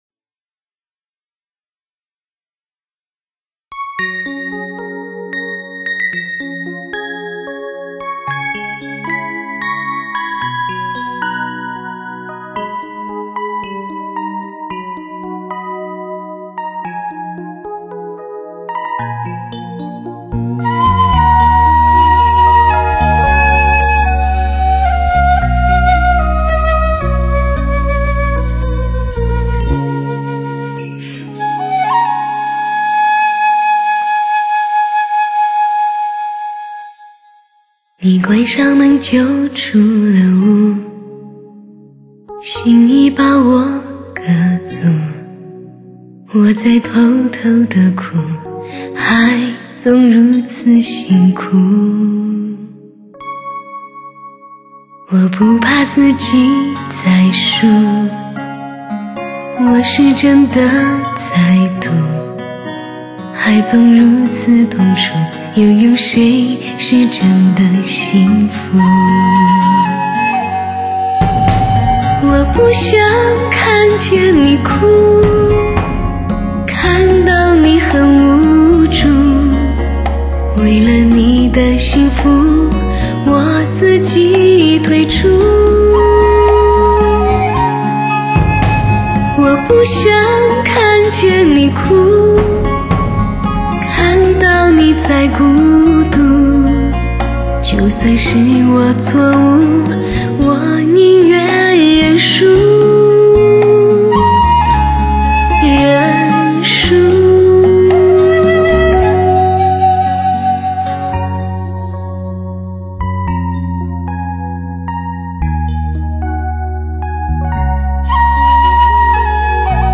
CD顶级模拟技术灵魂再现，日本直刻黑胶系列。